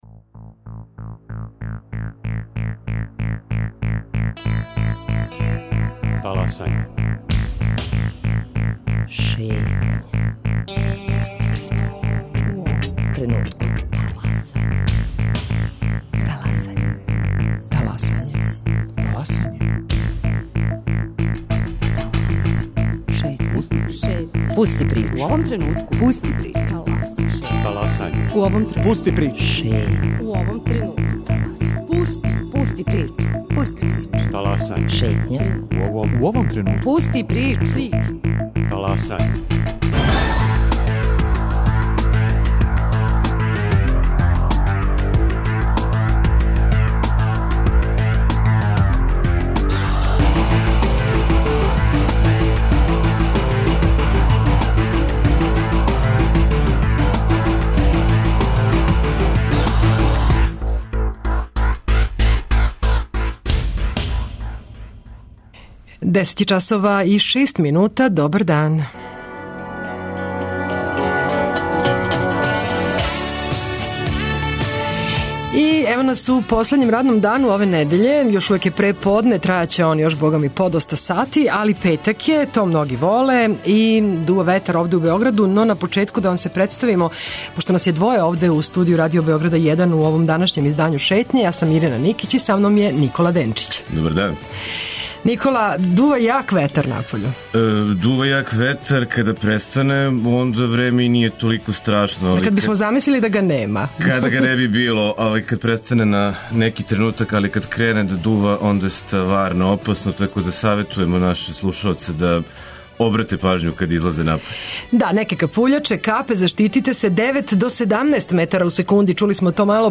Радио Београд 1, 10.05